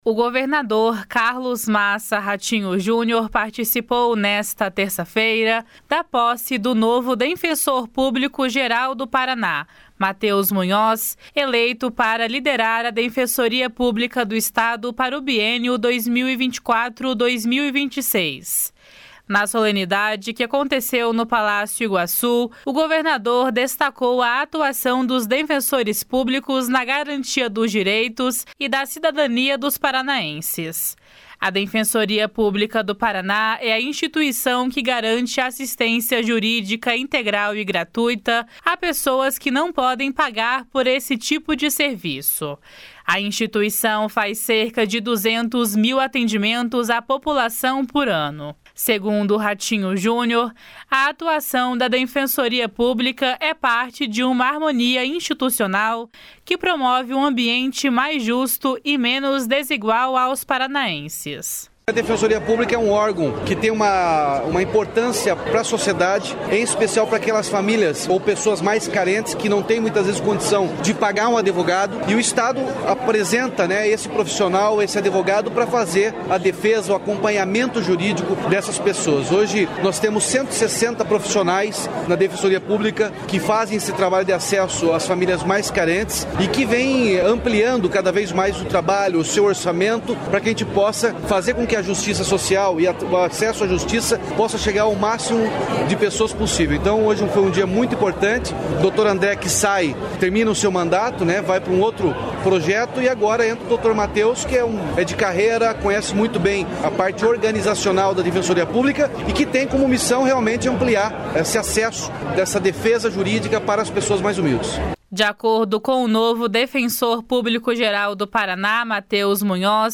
// SONORA RATINHO JUNIOR //
De acordo com o novo defensor público-geral do Paraná, Matheus Munhoz, o objetivo do órgão nos próximos anos é ampliar a atuação da instituição em várias áreas por meio das tecnologias digitais. // SONORA MATHEUS MUNHOZ //